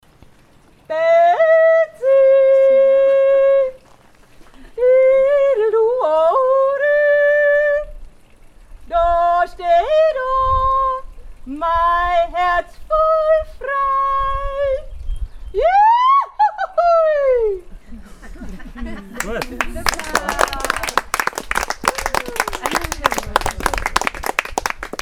und drei ganz persönliche Almschroa: